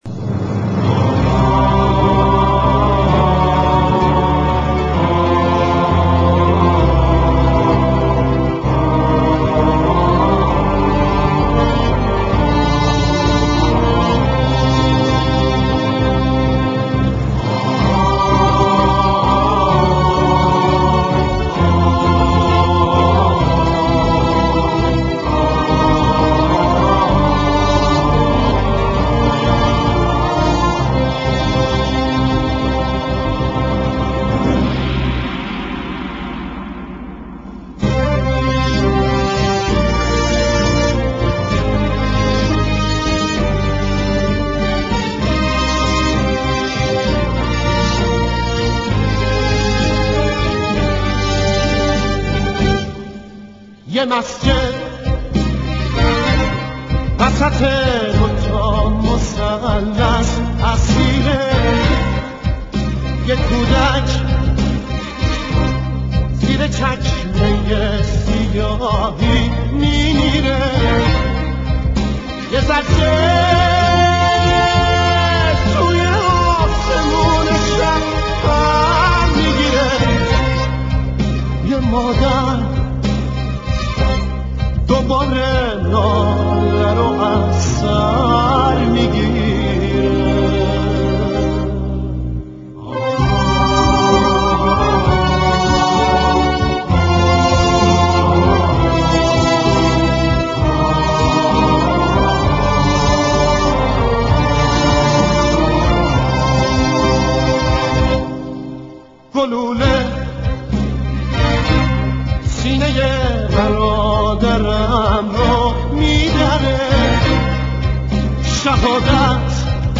ترانه حماسی
خواننده موسیقی پاپ